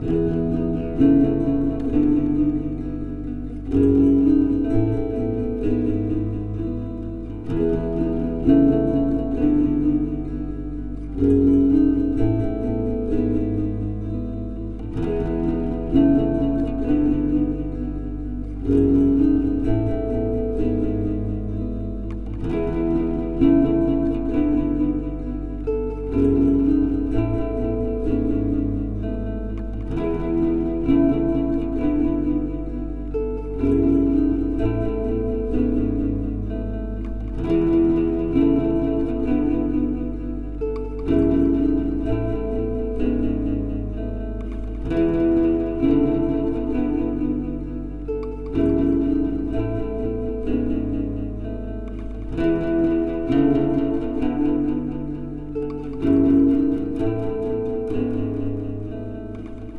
Recorded Bday 2010, Clips made August in Calais
Guitar_01_2_8bars.wav